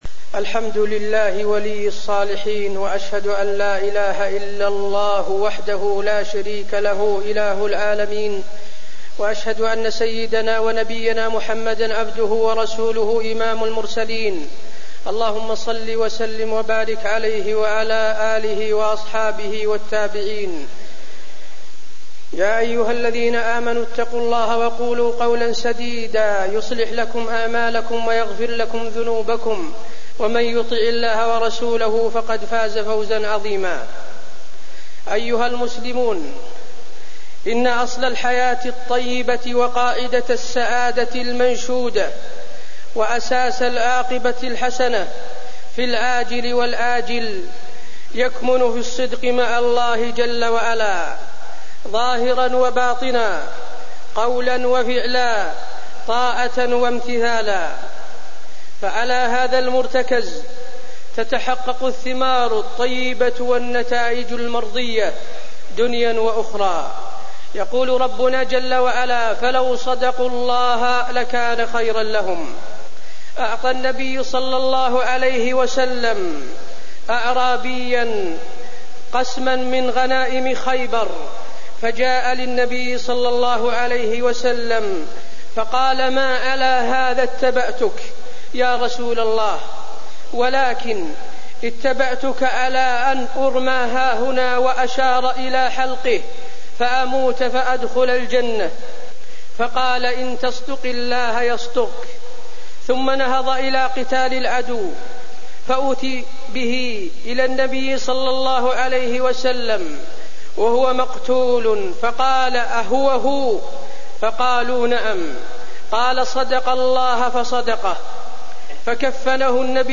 تاريخ النشر ٣٠ جمادى الأولى ١٤٣١ هـ المكان: المسجد النبوي الشيخ: فضيلة الشيخ د. حسين بن عبدالعزيز آل الشيخ فضيلة الشيخ د. حسين بن عبدالعزيز آل الشيخ الصدق مع الله